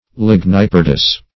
Search Result for " ligniperdous" : The Collaborative International Dictionary of English v.0.48: Ligniperdous \Lig`ni*per"dous\ (l[i^]g`n[i^]*p[~e]r"d[u^]s), a. [L. lignum wood + perdere to destroy: cf. F. ligniperde.]
ligniperdous.mp3